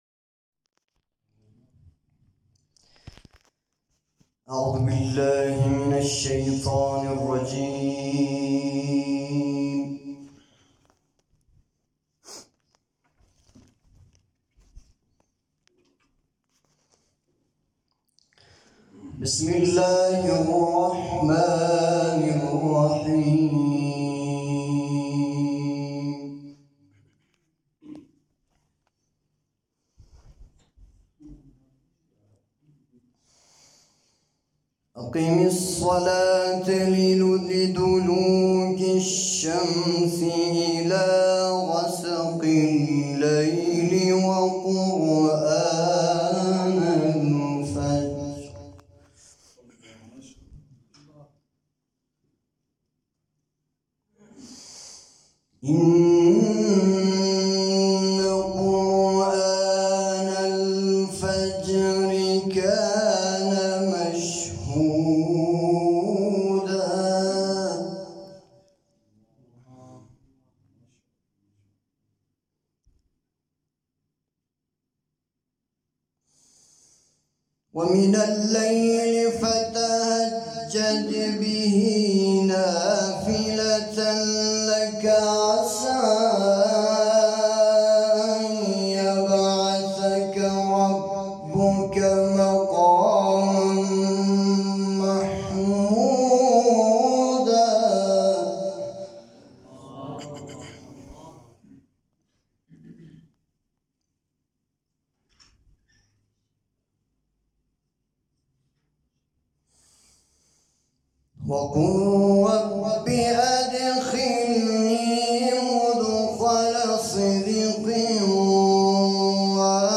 تلاوت سوره اسراء آیه ۷۸ الی ۸۲ و سوره کوثر